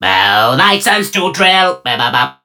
Vo_drill_sergeant_catchphrase_01.ogg